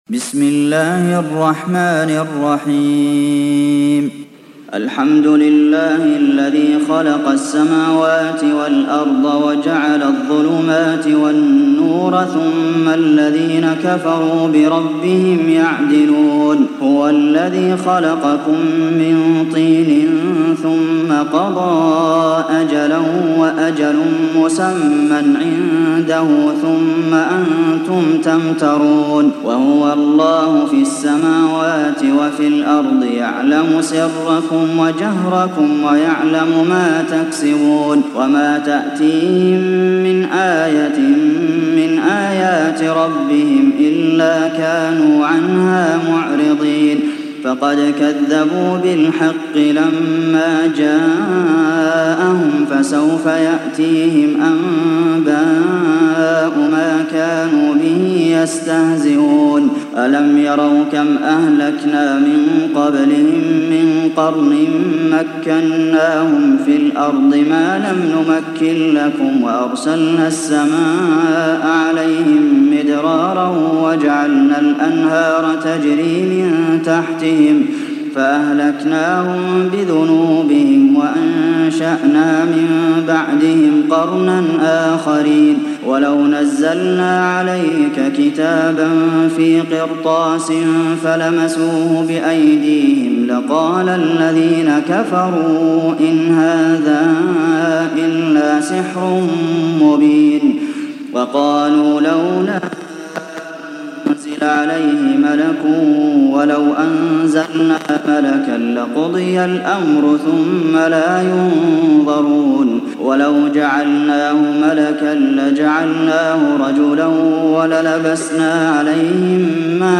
تحميل سورة الأنعام mp3 بصوت عبد المحسن القاسم برواية حفص عن عاصم, تحميل استماع القرآن الكريم على الجوال mp3 كاملا بروابط مباشرة وسريعة